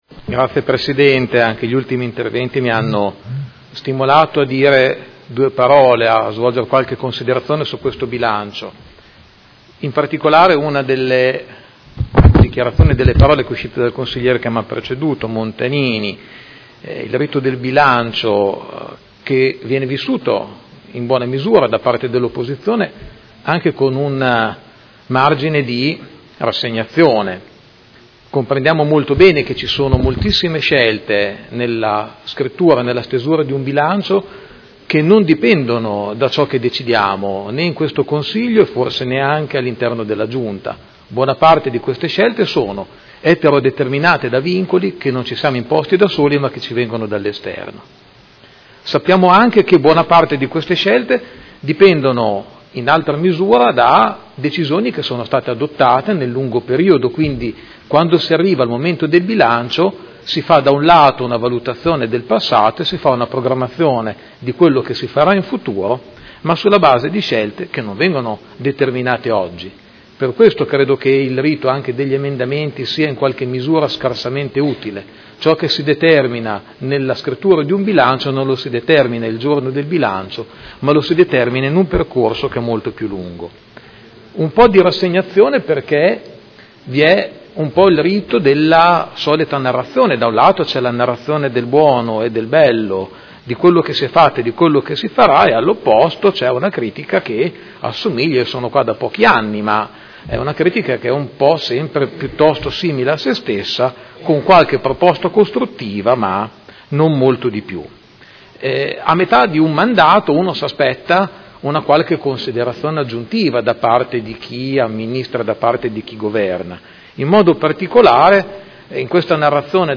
Giuseppe Pellacani — Sito Audio Consiglio Comunale
Seduta del 26 gennaio. Bilancio preventivo: Dibattito